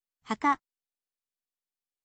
haka